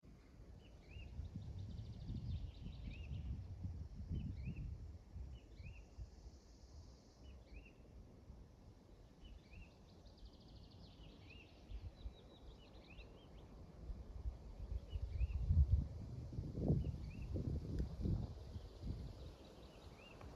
Sila cīrulis, Lullula arborea
StatussDzirdēta balss, saucieni